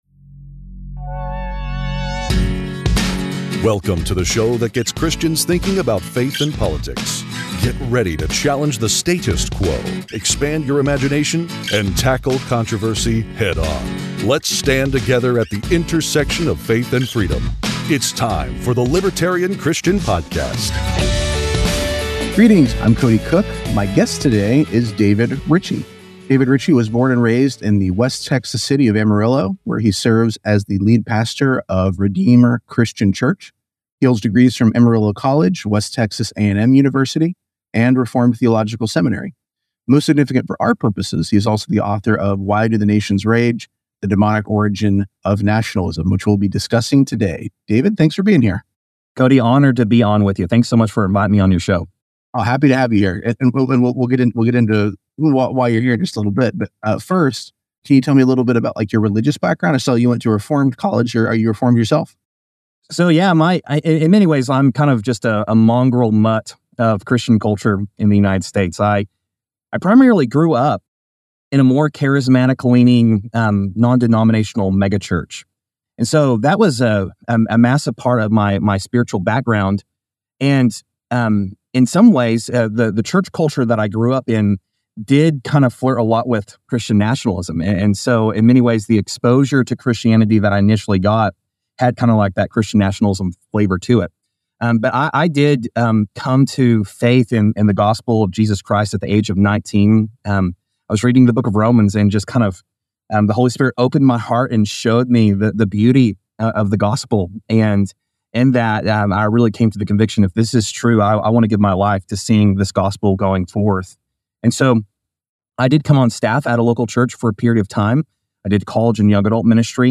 The conversation addresses the contemporary relevance of nationalism and its potential to distort Christian faith.